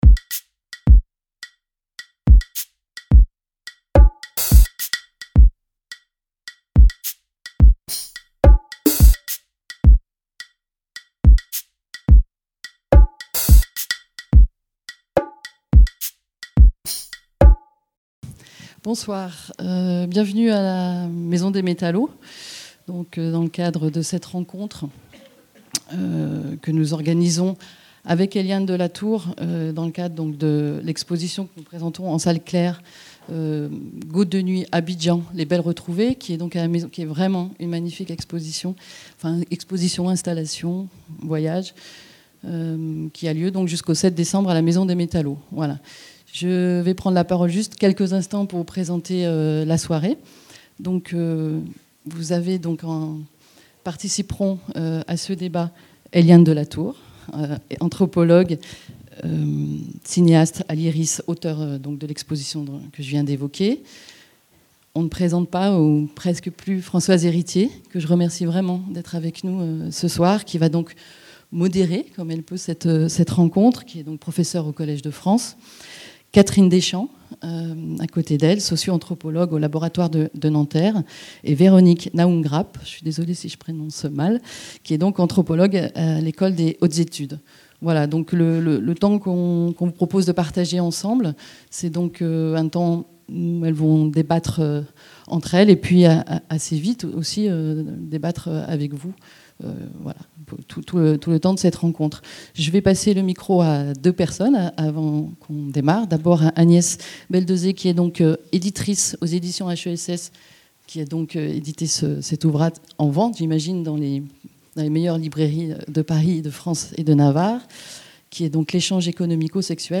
1- Conférence - Femmes victimes de violences dans la sphère publique.
Débat modéré par Françoise Héritier, Collège de France.